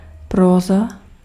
Ääntäminen
Ääntäminen Tuntematon aksentti: IPA: /ˈprɔːza/ Haettu sana löytyi näillä lähdekielillä: tšekki Käännös Ääninäyte Substantiivit 1. prose {f} France France Suku: f .